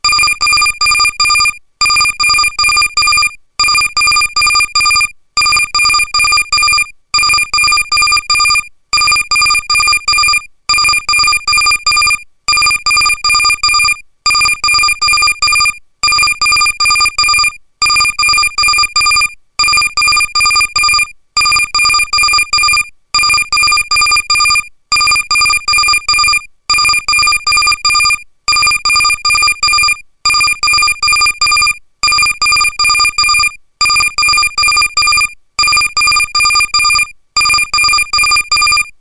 ピリリッピリリッピリリッ・・・。
エレガントで洗練された着信音です。この作品では、その上品な響きと魅力的なメロディーが、着信時の特別な瞬間を演出します。